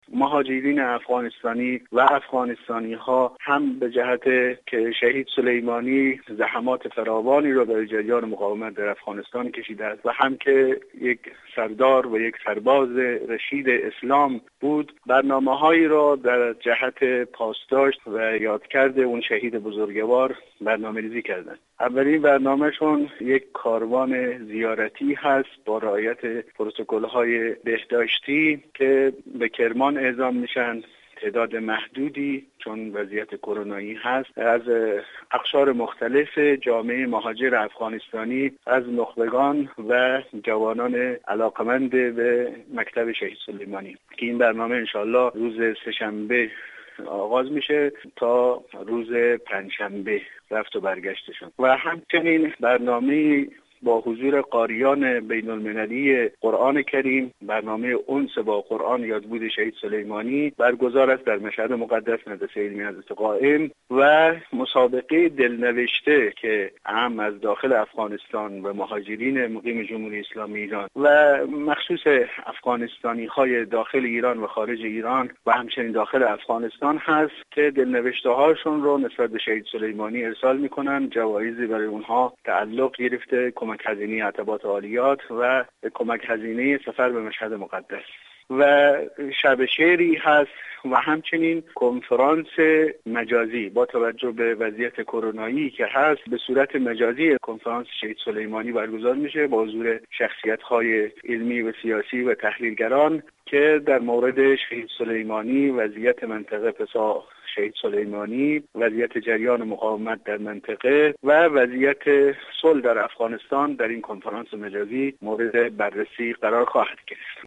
در گفتگو با خبر رادیو زیارت گفت :